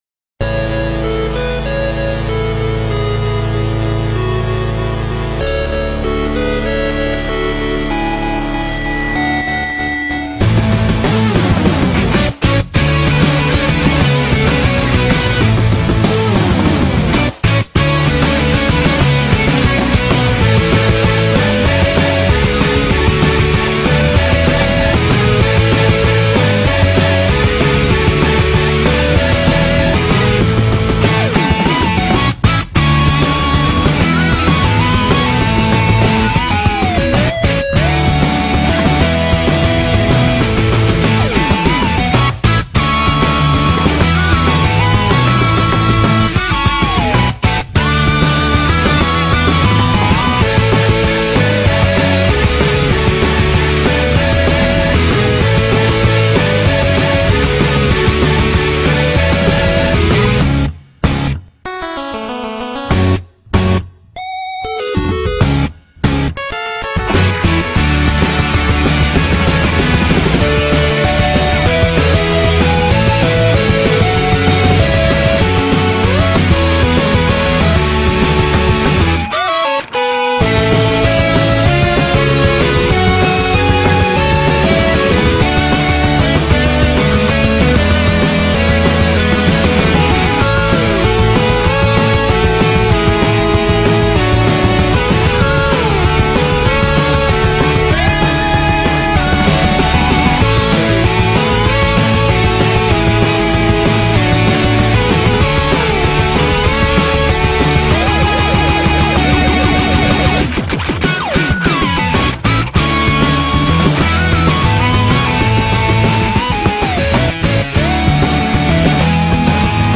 BPM96-192
Audio QualityCut From Video